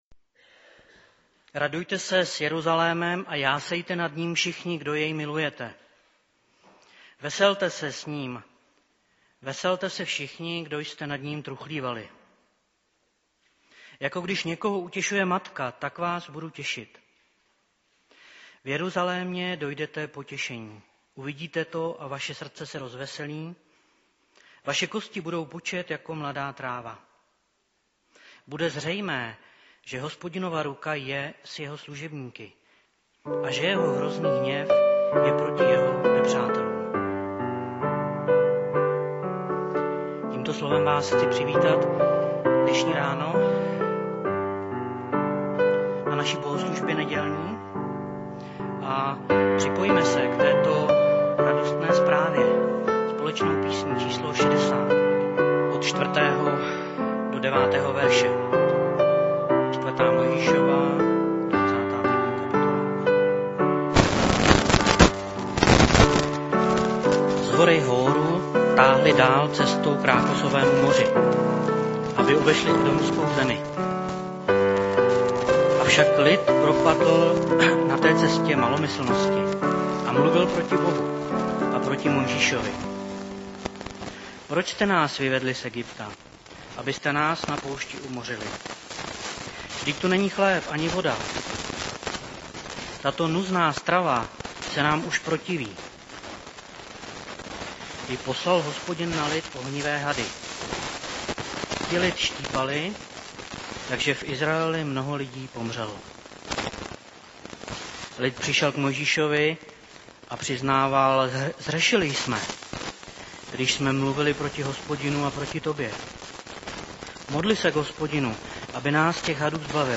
Postní kázání
Nedělní bohoslužby Husinec přehrát